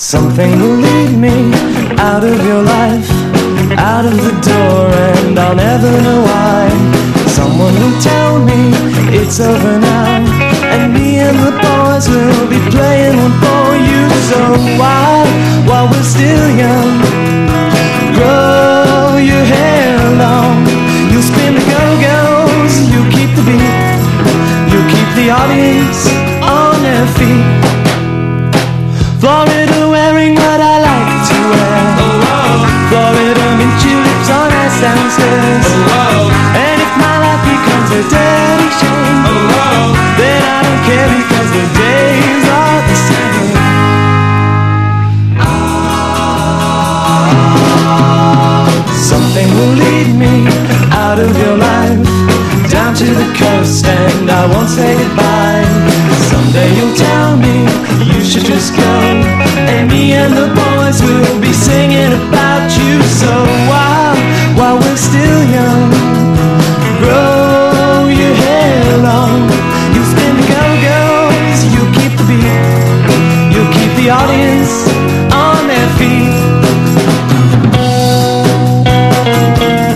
¥4,980 (税込) ROCK / SOFTROCK.